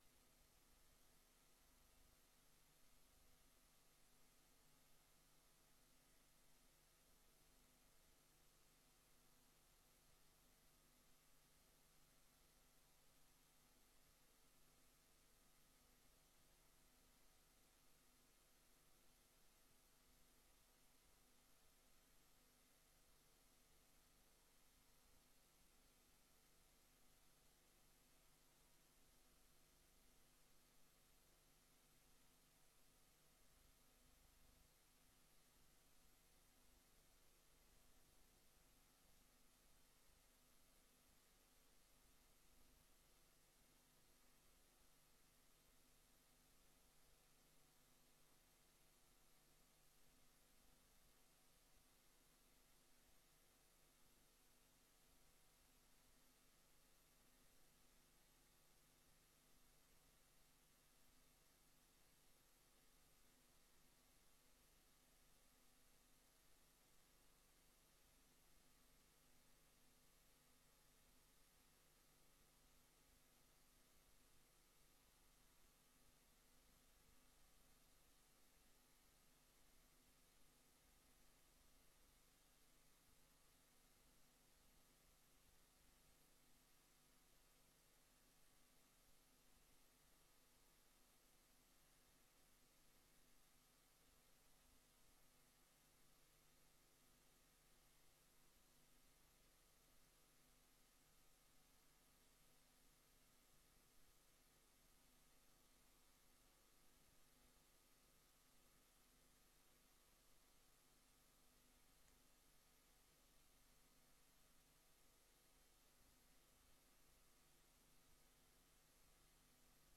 Raadsvergadering Papendrecht 27 maart 2025 20:00:00, Gemeente Papendrecht
Locatie: Raadzaal